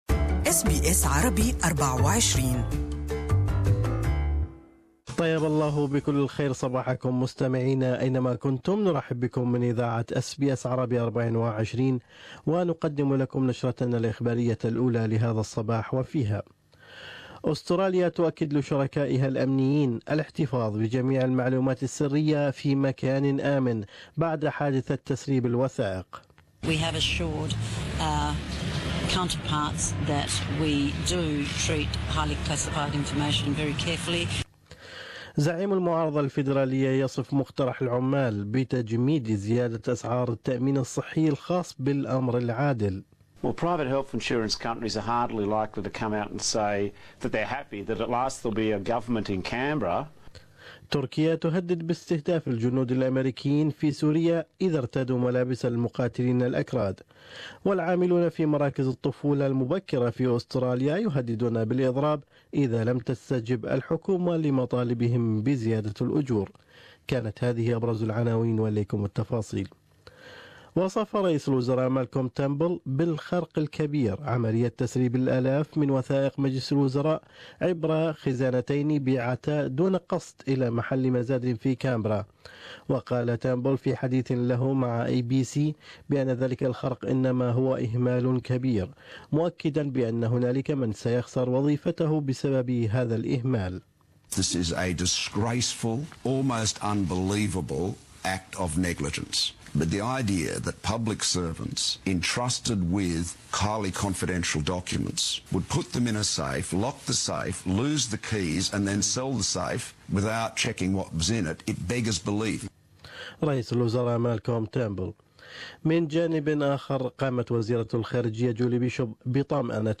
News Bulletin: Robert Doyle quits as Melbourne's Lord Mayor